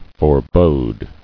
[fore·bode]